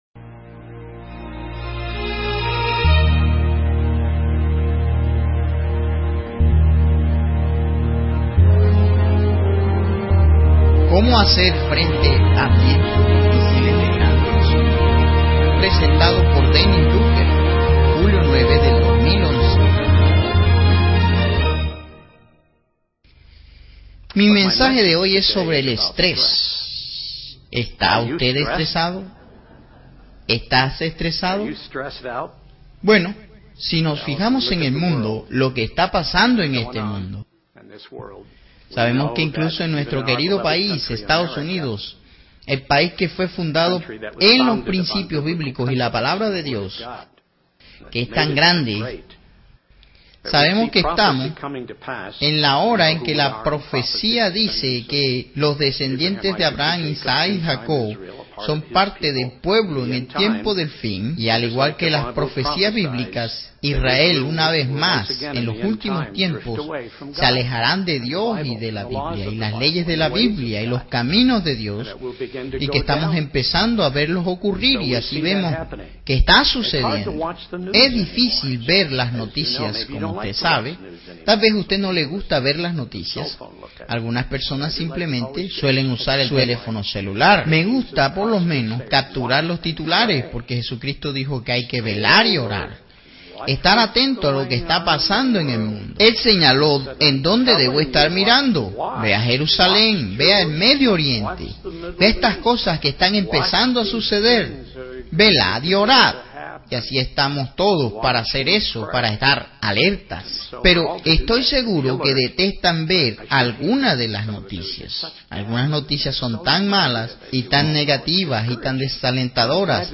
Sermones
Given in Ciudad de México